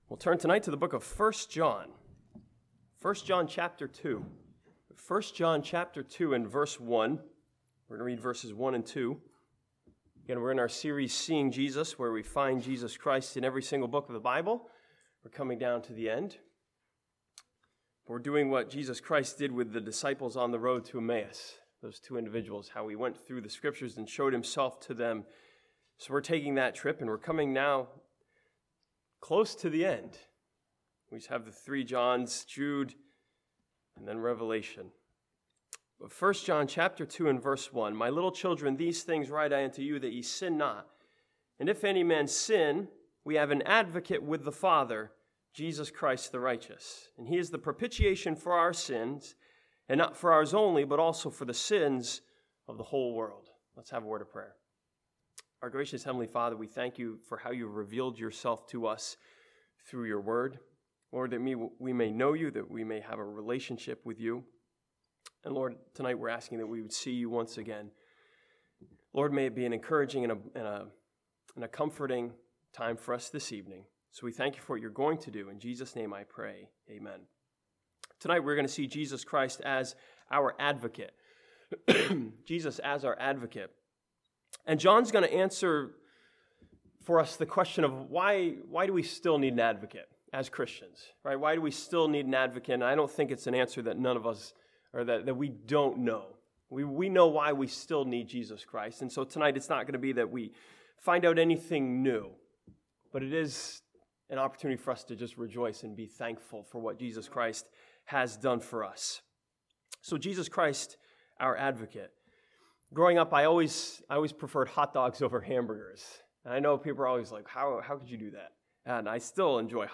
This sermon from 1 John chapter 2 see Jesus as our Advocate who is essential is enabling us to have fellowship with God.